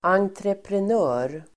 Ladda ner uttalet
entreprenör substantiv, contractor Uttal: [angtrepren'ö:r (el. en-)] Böjningar: entreprenören, entreprenörer Synonymer: företagare Definition: person som åtar sig entreprenad (a person who undertakes contract work)